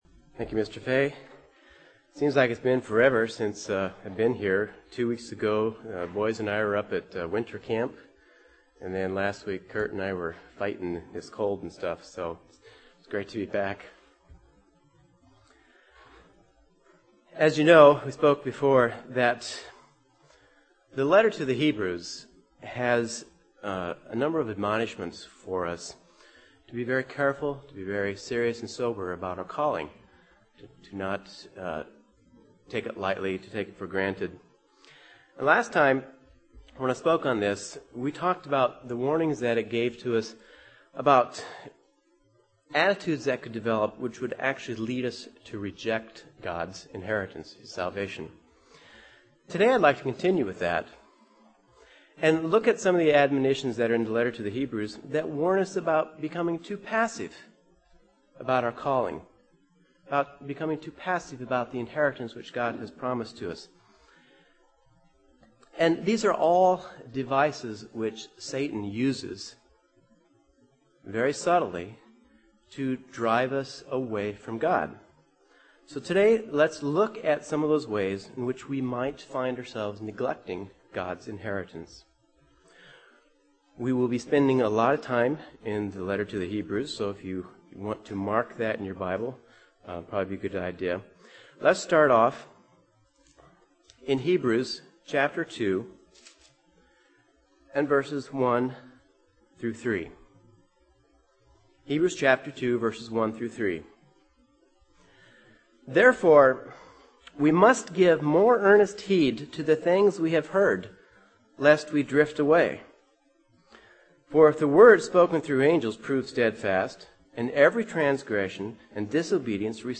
Given in Chicago, IL
UCG Sermon Studying the bible?